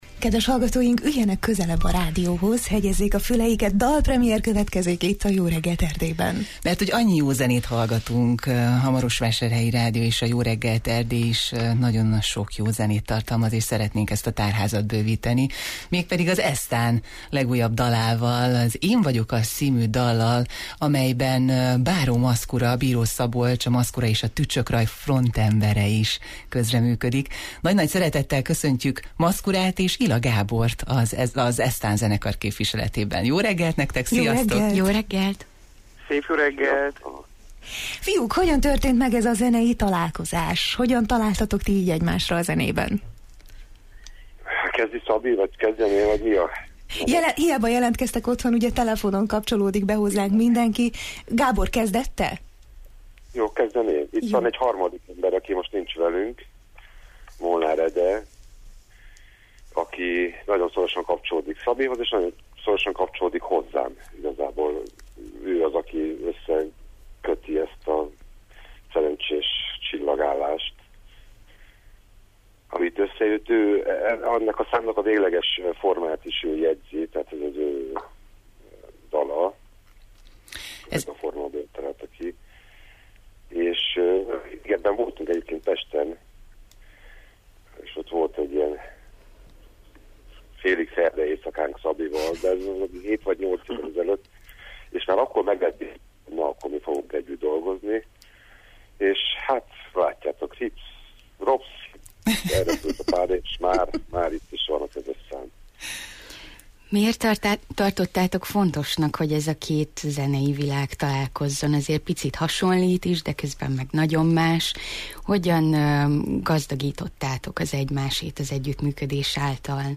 Megtudják beszélgetésünkből: